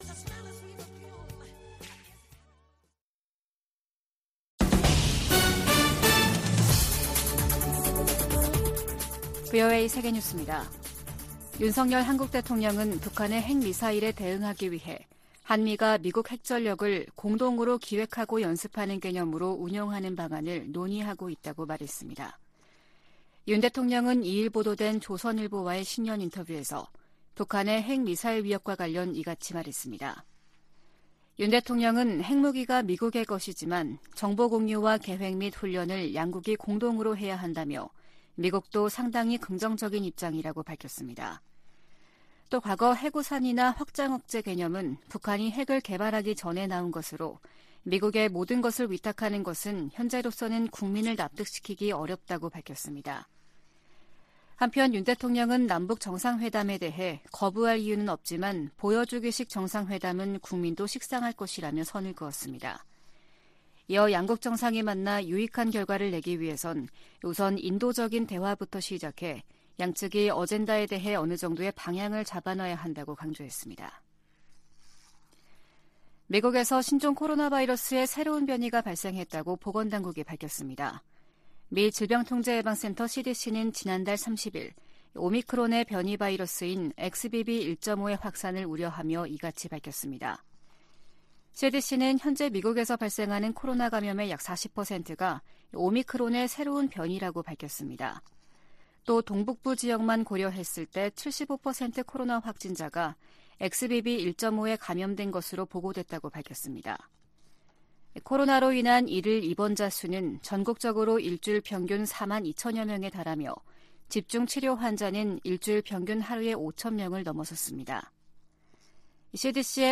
VOA 한국어 아침 뉴스 프로그램 '워싱턴 뉴스 광장' 2023년 1월 3일 방송입니다. 미국 국무부가 이틀 연속 탄도미사일을 발사한 북한을 비판했습니다.